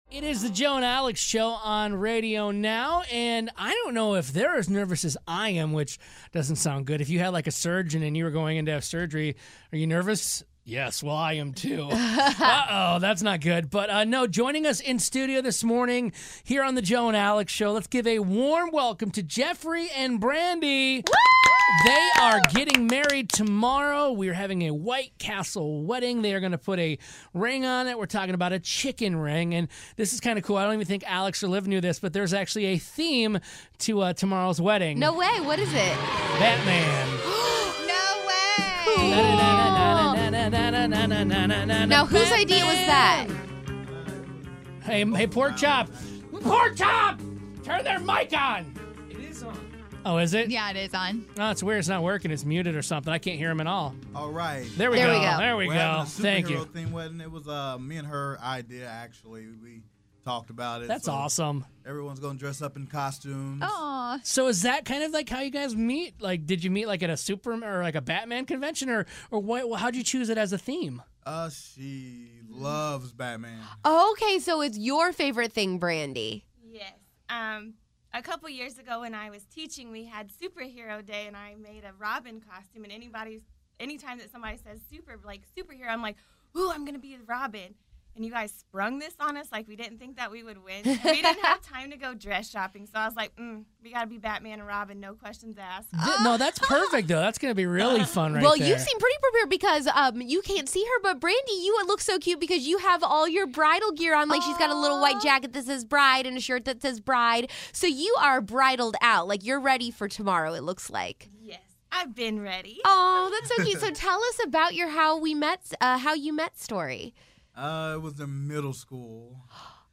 White Castle Wedding Winners In Studio!